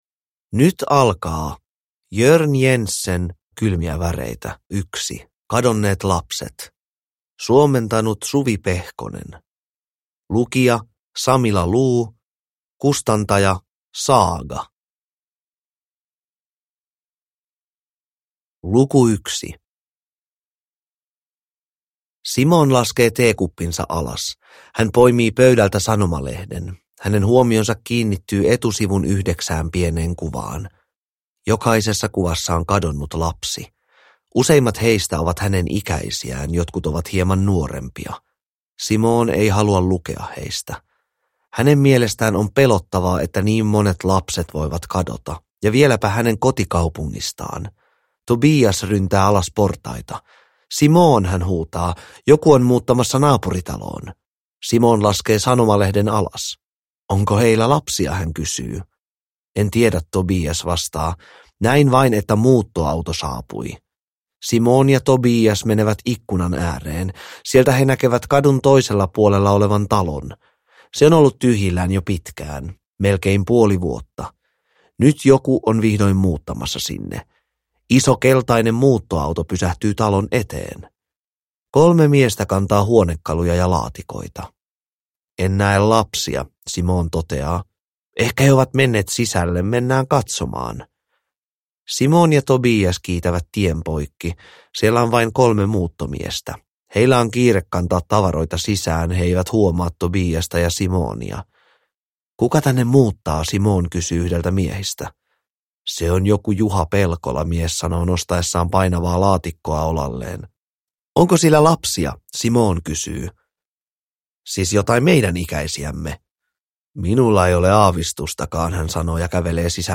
Kylmiä väreitä 1: Kadonneet lapset (ljudbok) av Jørn Jensen